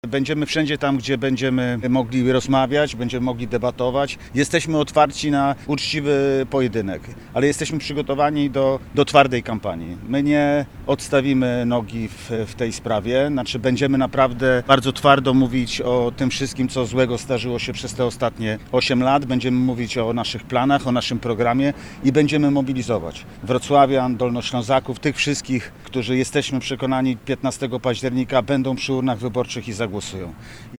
Konferencja prasowa odbyła się z udziałem wszystkich kandydatów i kandydatek KO.
-Jesteśmy otwarci na uczciwy pojedynek, mówił poseł Grzegorz Schetyna, który będzie kandydował do Senatu.